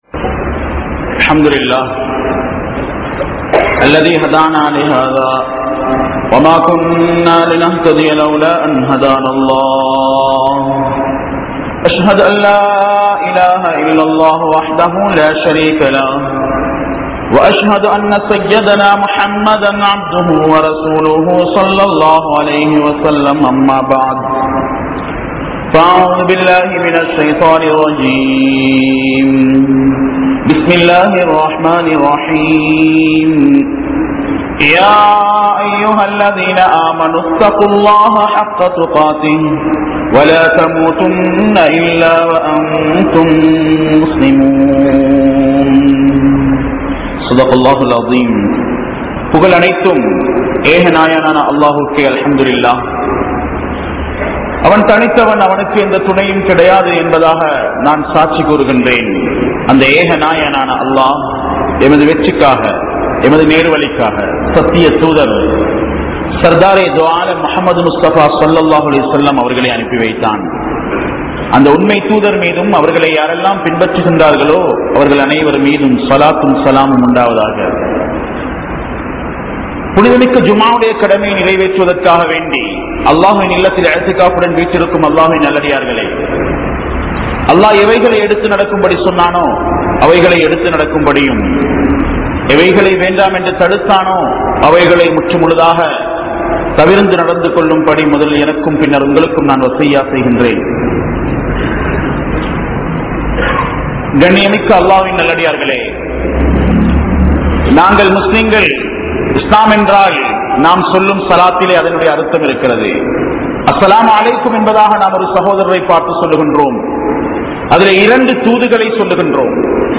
Manitha Unarvuhalai Mathiungal (மனித உணர்வுகளை மதியுங்கள்) | Audio Bayans | All Ceylon Muslim Youth Community | Addalaichenai
Kandy, Katugasthoata Jumua Masjith